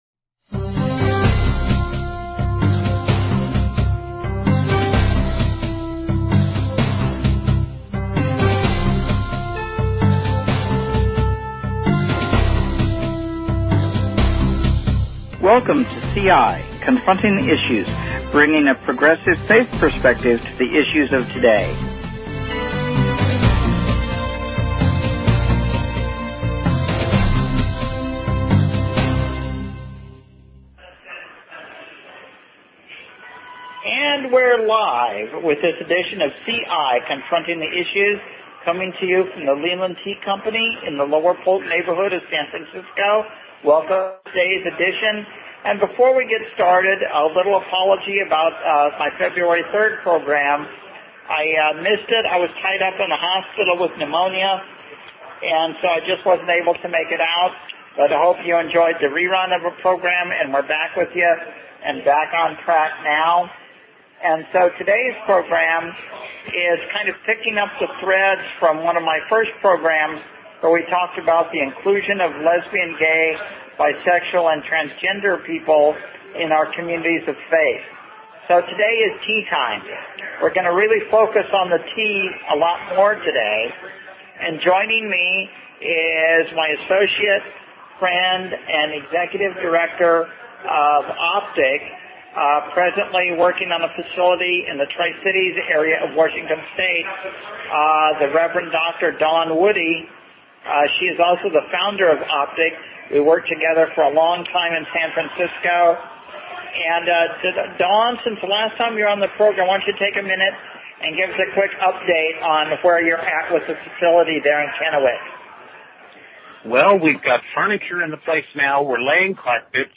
Talk Show Episode, Audio Podcast, CI_Confronting_the_Issues and Courtesy of BBS Radio on , show guests , about , categorized as
Live from the Leland Tea Company, in San Francisco. Bringing a progressive faith perspective to the issues of the day.